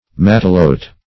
matelote - definition of matelote - synonyms, pronunciation, spelling from Free Dictionary
Matelote \Mat"e*lote\ (m[a^]t"[-e]*l[=o]t), Matelotte